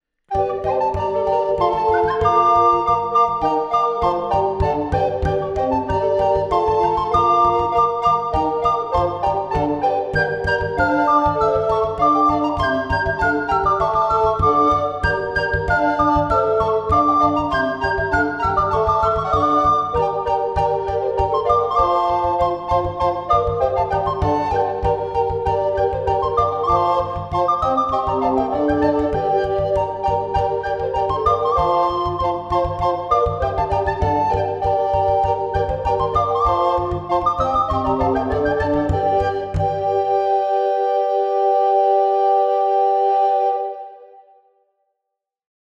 A-Carousing-Consort-Bransle-de-Montirande.mp3